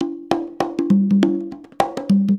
100 CONGAS02.wav